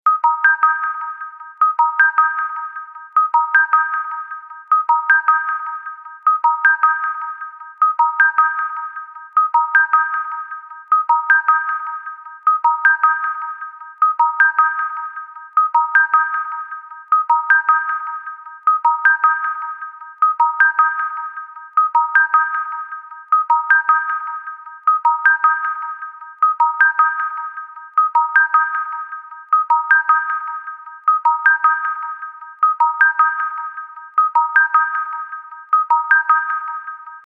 穏やかで心地よい環境音楽の中でリラックスした瞬間を提供します。
この着信音は、優れた柔らかいサウンドが繰り返し響くことで、穏やかな雰囲気を醸し出すこと間違いなしです。